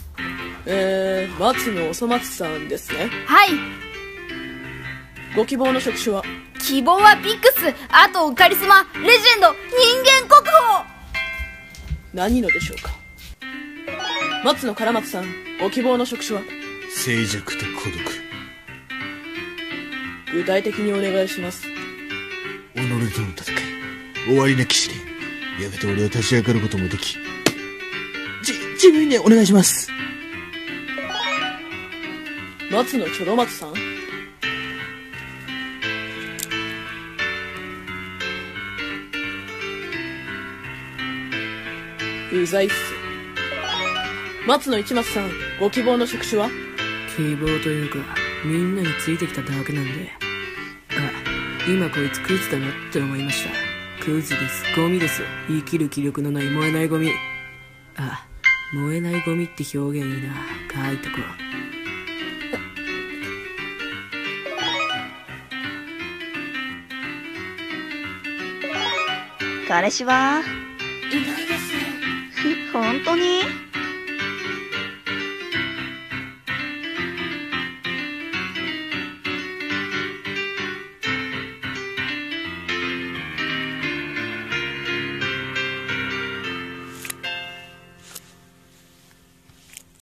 【コラボ声劇】アニメおそ松さん「就職しよう」よりハローワークでのそれぞれの希望職種